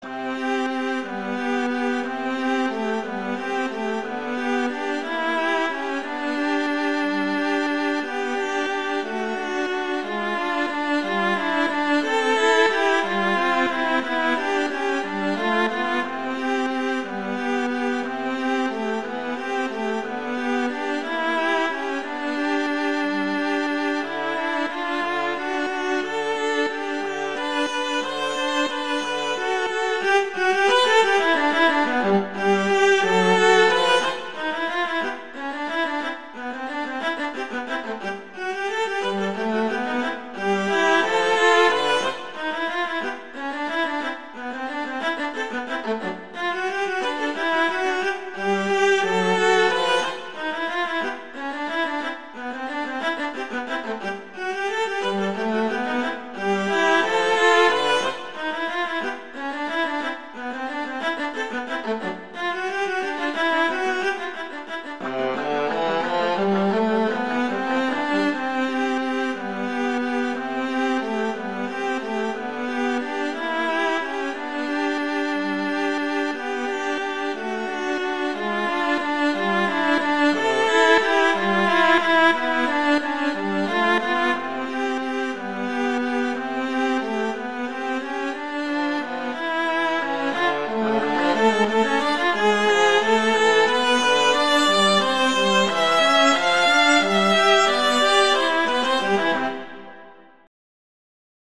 Instrumentation: two violas
Note Range: C3-F5
classical, children
C major
♩=180 BPM (real metronome 176 BPM)